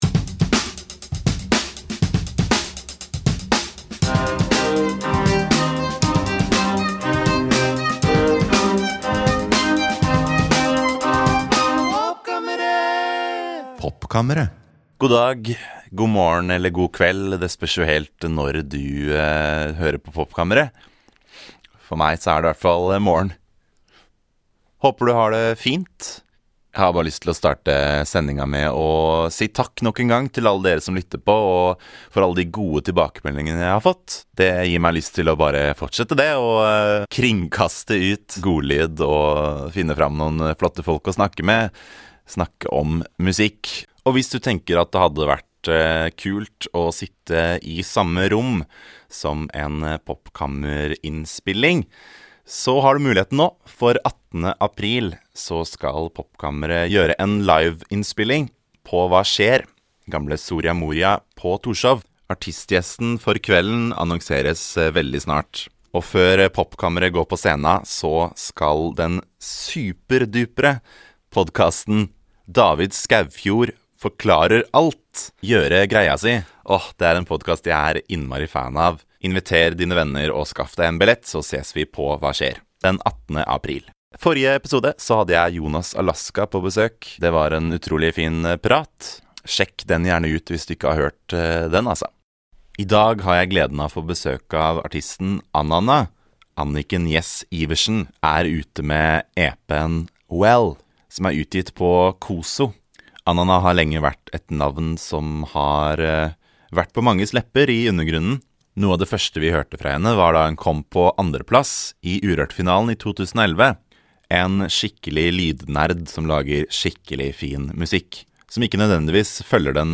Vi lytter også til den gryende generasjons godlyd fra Russland og Australia.